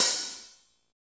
SLOP OHH.wav